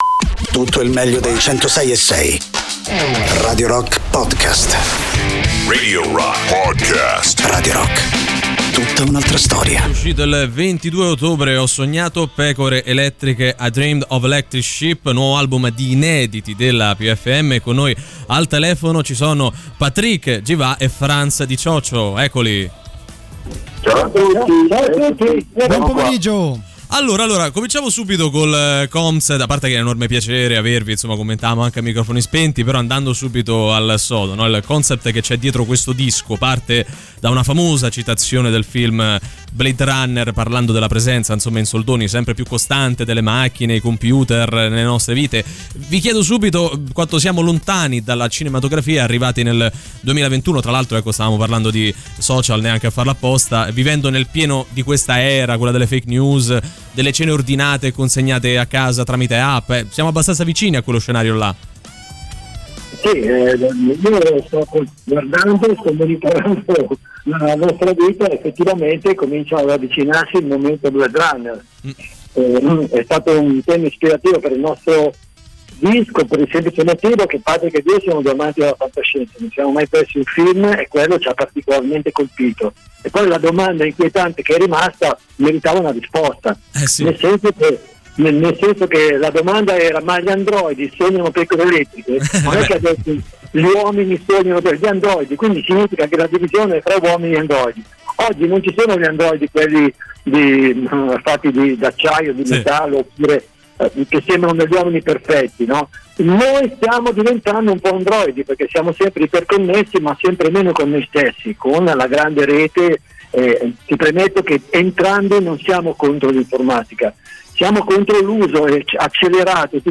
Interviste: PFM (10-11-21)